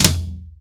TOM     4B.wav